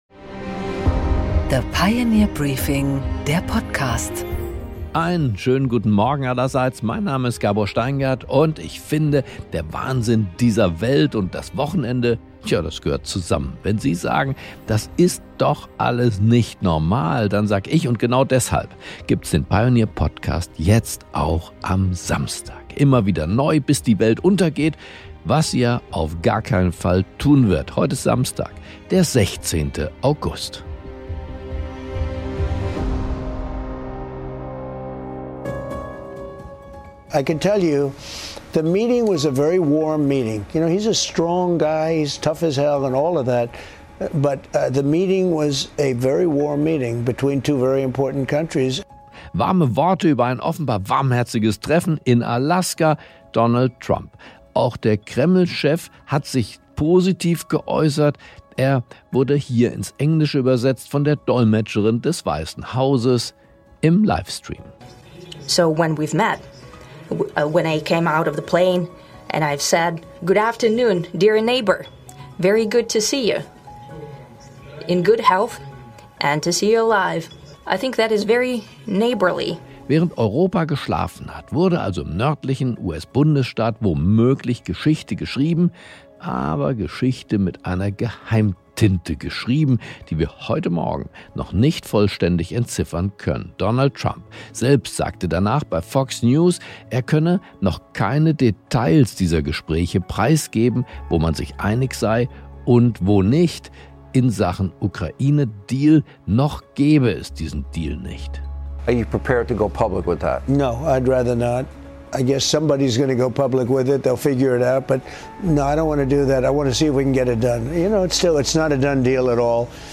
Gabor Steingart präsentiert die Pioneer Briefing Weekend Edition